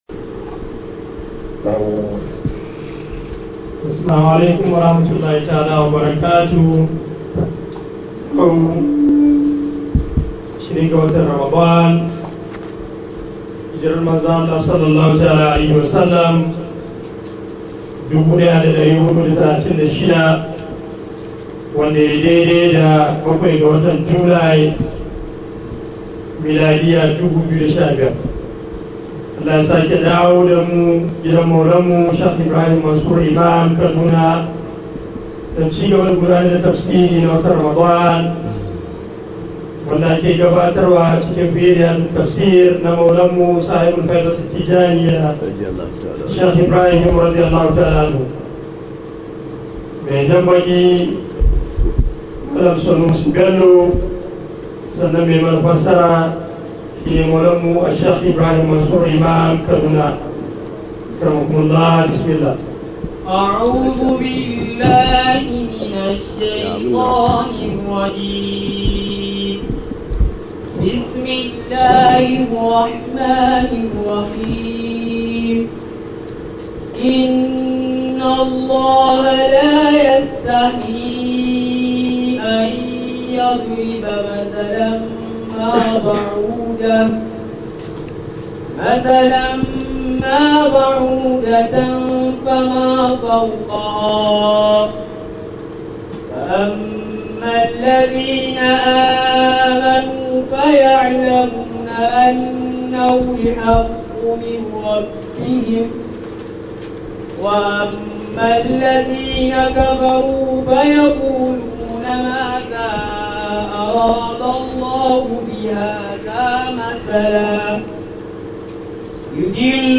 013 HAYATUDEEN ISLAMIC STUDIO TAFSIR 2015.mp3